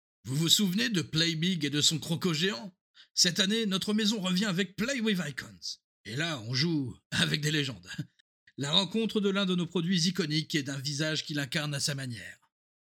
Audition Lacoste
25 - 50 ans - Baryton